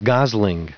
Prononciation du mot gosling en anglais (fichier audio)
Prononciation du mot : gosling